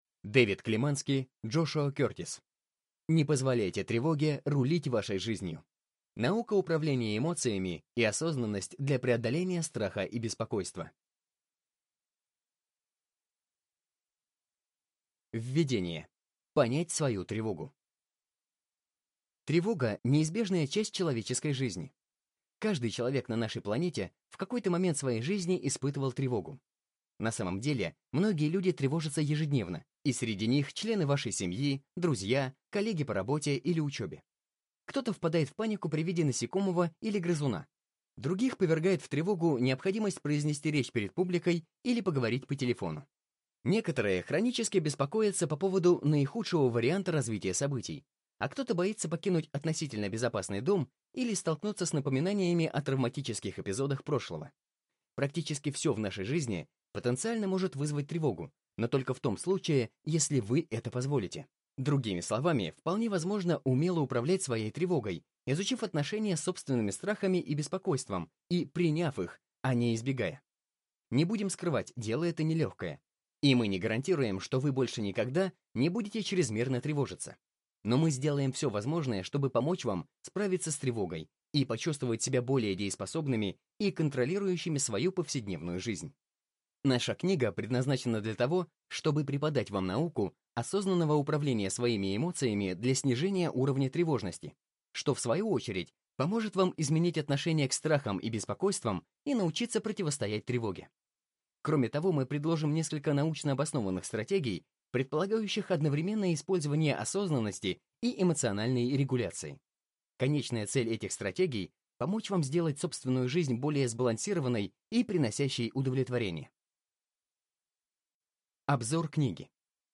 Аудиокнига Не позволяйте тревоге рулить вашей жизнью. Наука управления эмоциями.